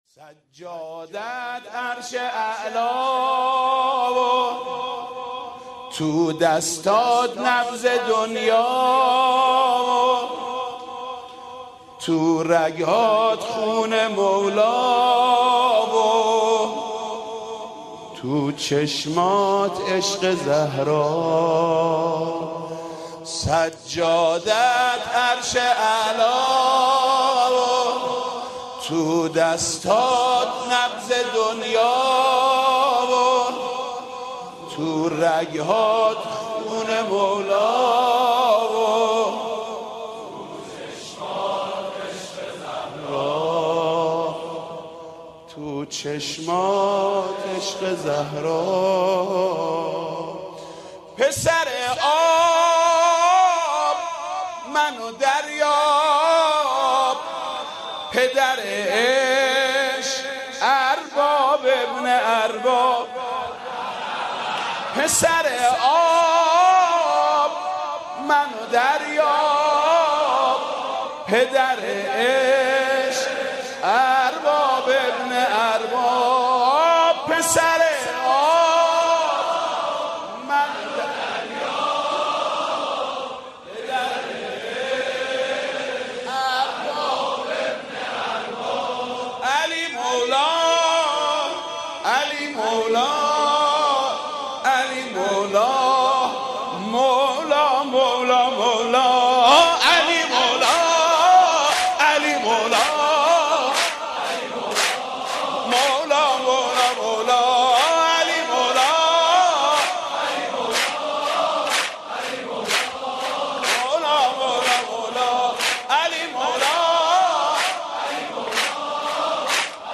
مولودی خوانی حاج محمود کریمی به مناسبت ولادت امام سجاد علیه السلام